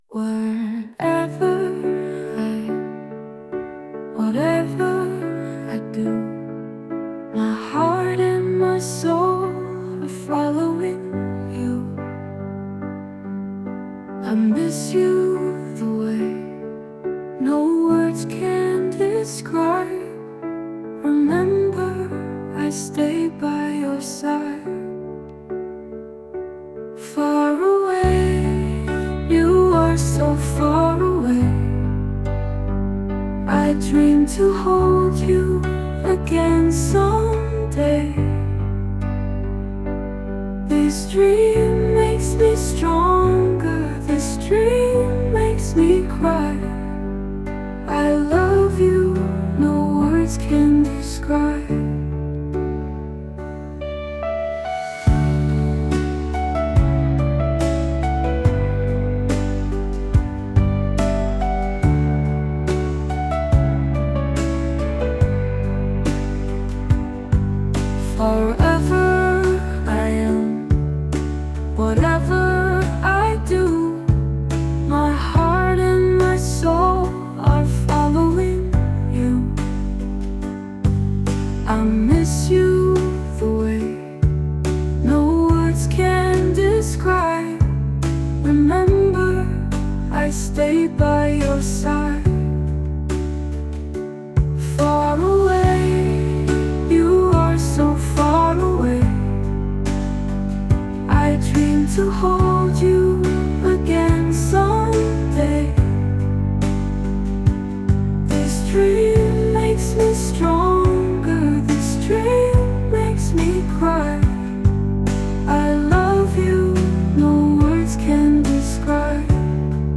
Музика і виконання ШІ
ТИП: Пісня
СТИЛЬОВІ ЖАНРИ: Ліричний